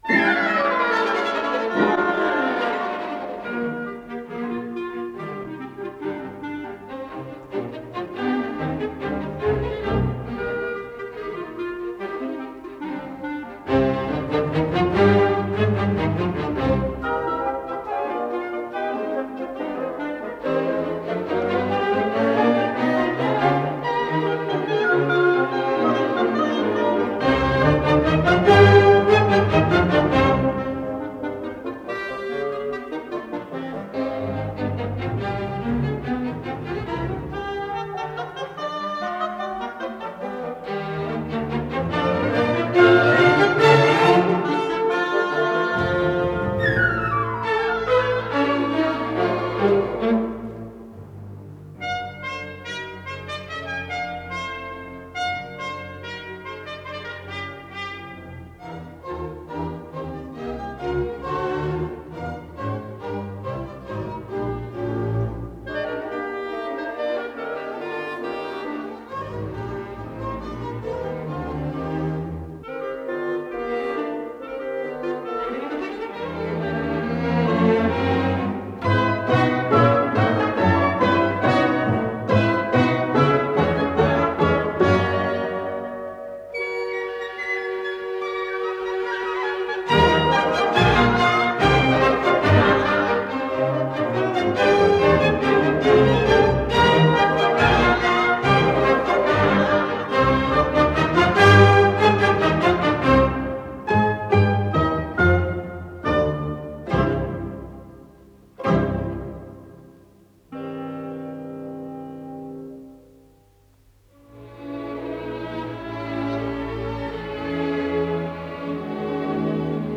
с профессиональной магнитной ленты
ПодзаголовокИз цикла "Деревенские концерты" ("Развлечения") для малого оркестра.
Ми бемоль мажор
Содержание3. Аллегро виво
ИсполнителиАнсамбль солистов Государственного академического оркестра СССР
Дирижёр - Владимир Вербицкий
Скорость ленты38 см/с
ВариантДубль моно